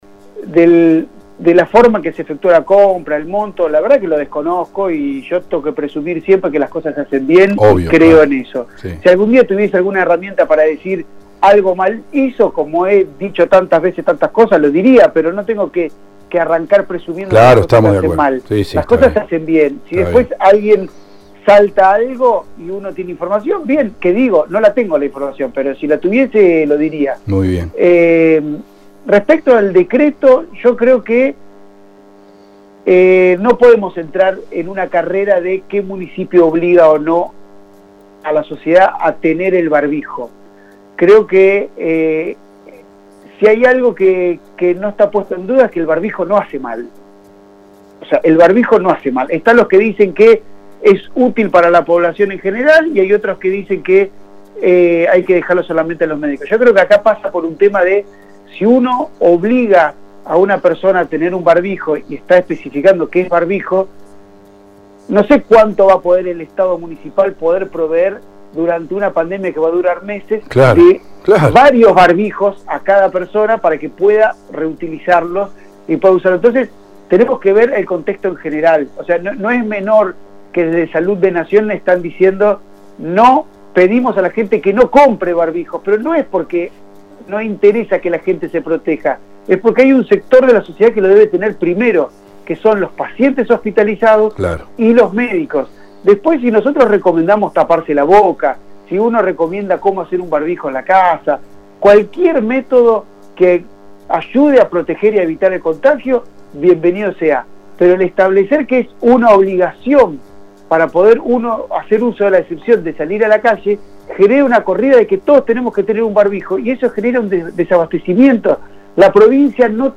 DR. MARCELO MATZKIN, PRESIDENTE BLOQUE PRO-CAMBIEMOS.
Dialogamos con el Dr. Marcelo Matzkin, presidente del bloque del PRO-Cambiemos que aseguró que no fueron consultados, que el HCD no está sesionando y que, en principio, personalmente “cree en las buenas intenciones, lo que no quita que luego se analicen los procedimientos utilizados”.